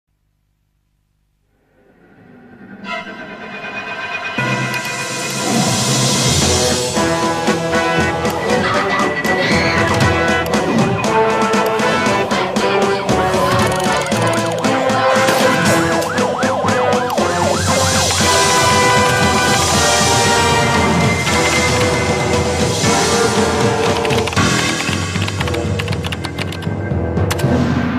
короткие
подозрительные